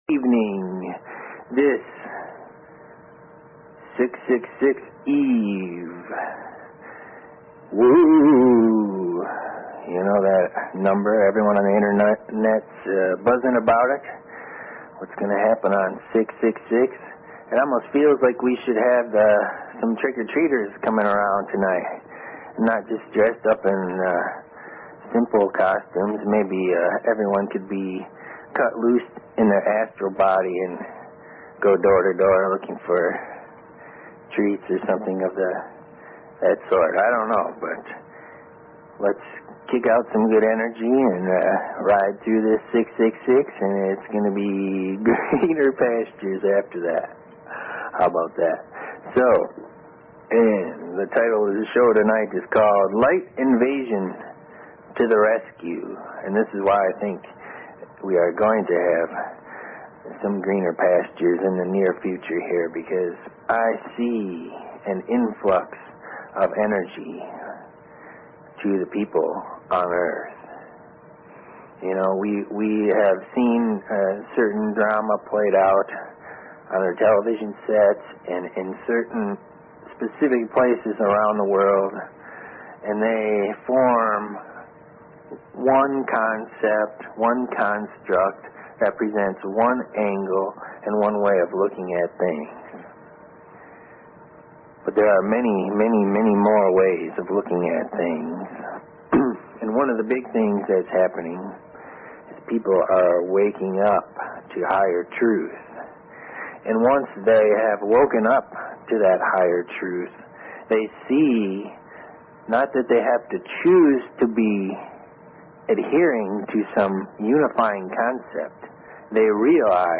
Talk Show Episode, Audio Podcast, TWM and Courtesy of BBS Radio on , show guests , about , categorized as